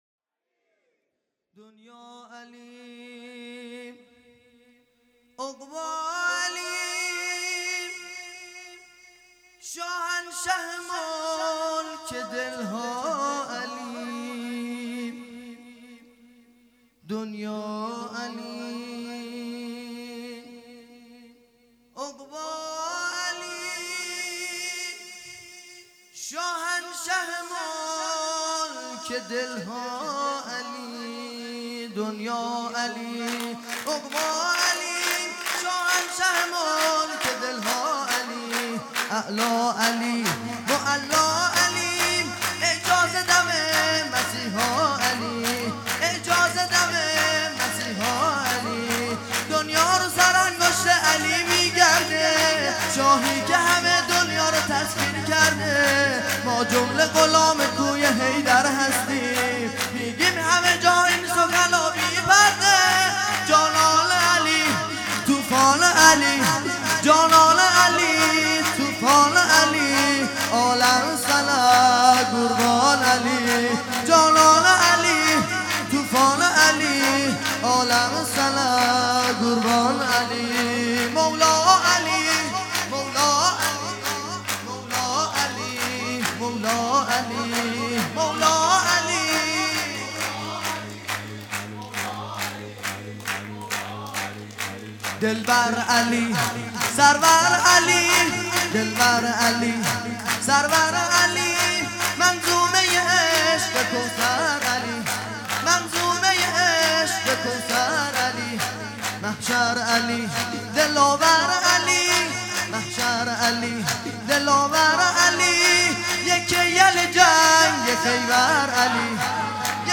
هیئت دانشجویی فاطمیون دانشگاه یزد
سرود
ولادت امام باقر (ع) | ۱۴ اسفند ۹۷